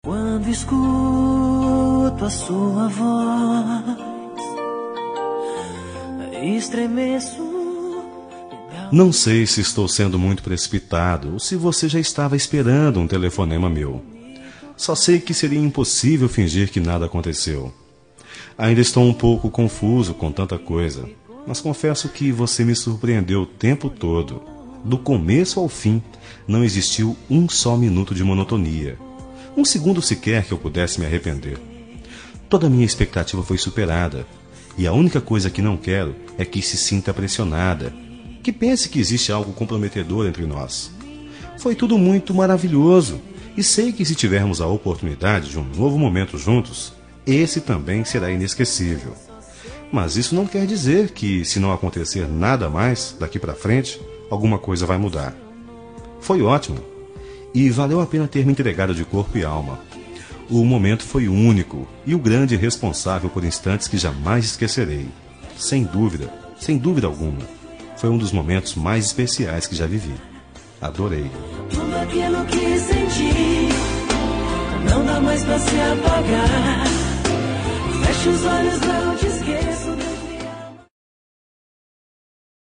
Telemensagem Momentos Especiais – Voz Masculina – Cód: 201888 – Adorei a Noite